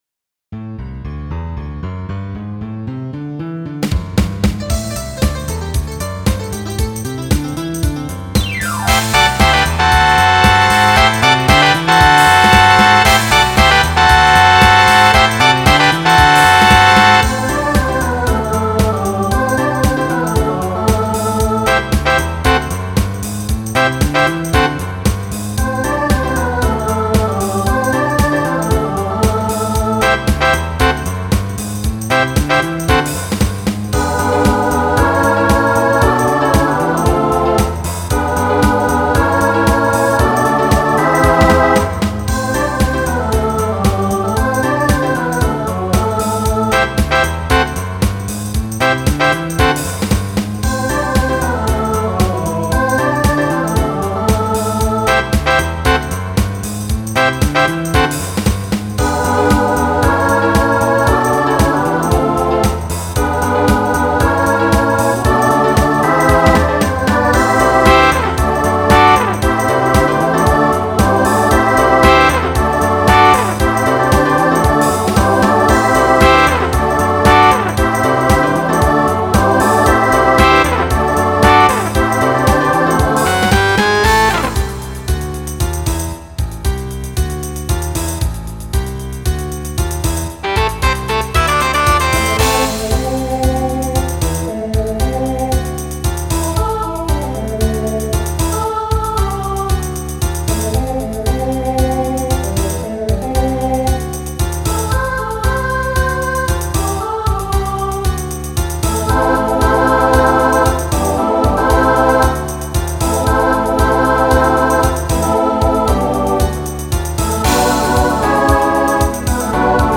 Voicing SATB Instrumental combo Genre Pop/Dance
1980s Show Function Mid-tempo